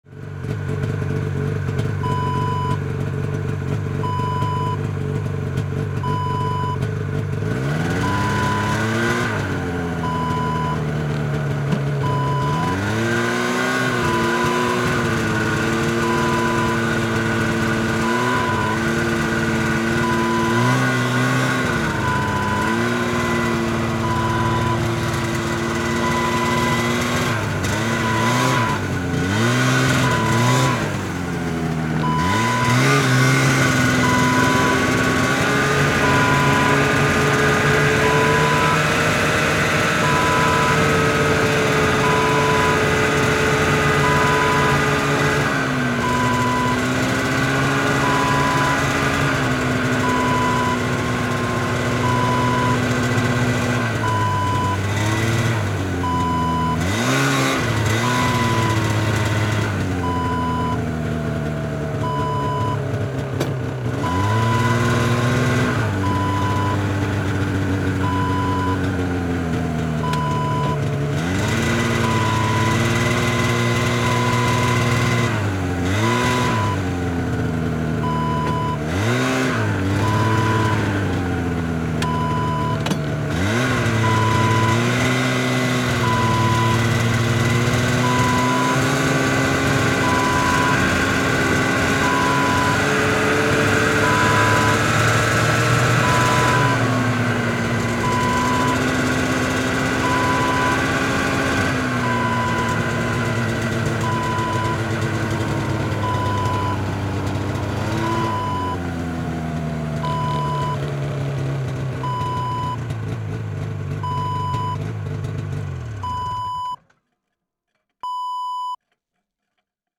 Snowmobile: riding sound effect .wav #2
Description: A person riding a snowmobile
Properties: 48.000 kHz 24-bit Stereo
A beep sound is embedded in the audio preview file but it is not present in the high resolution downloadable wav file.
snowmobile-ride-preview-2.mp3